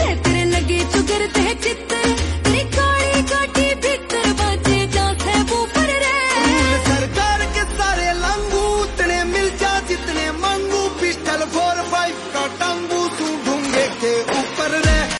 Haryanvi Songs
High-Quality Free Ringtone